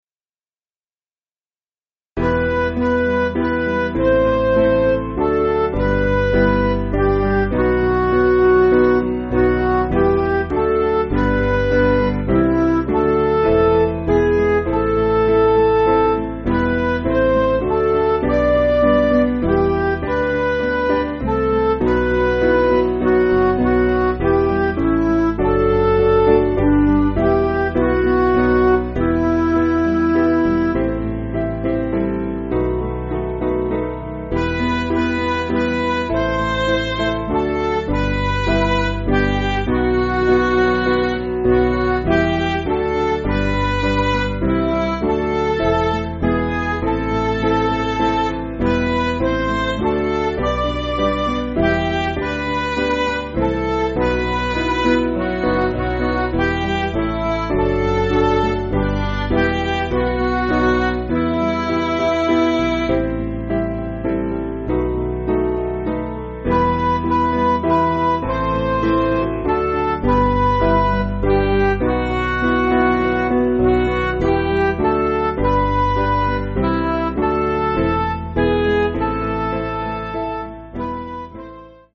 Piano & Instrumental
(CM)   4/Em